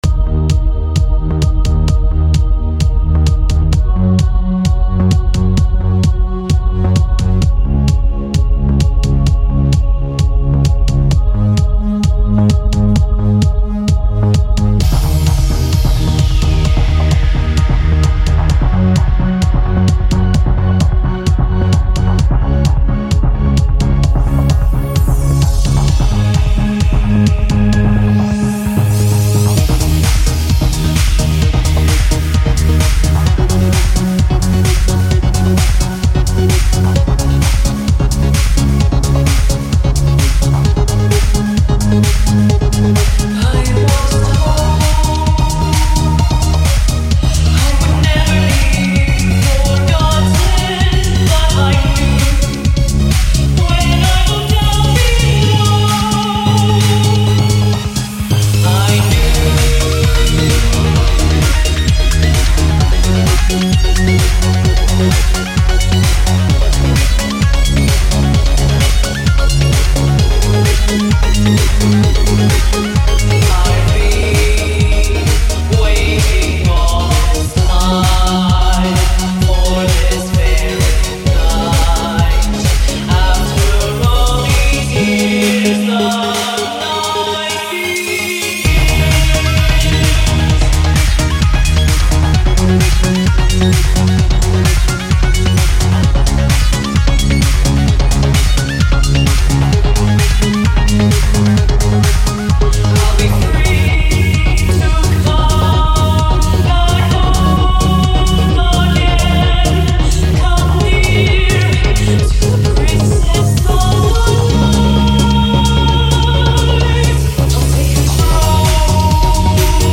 great layers and composition
energetic mix of Darkwave and Trance